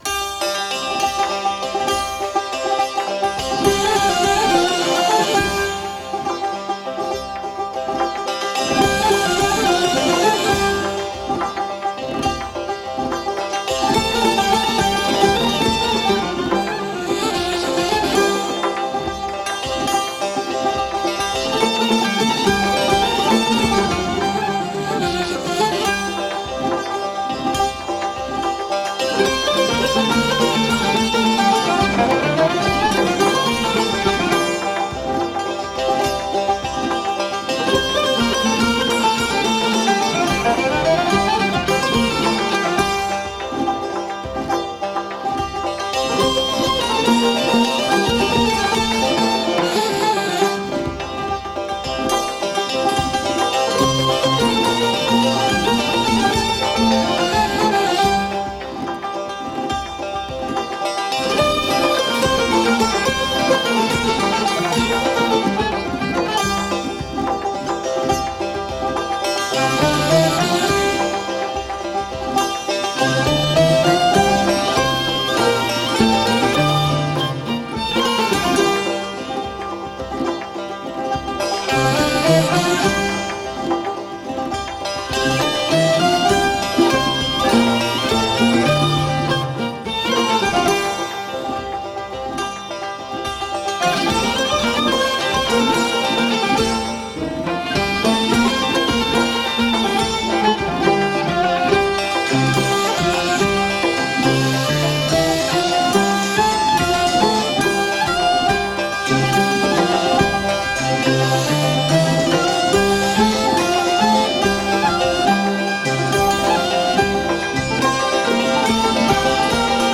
Chaharmezrab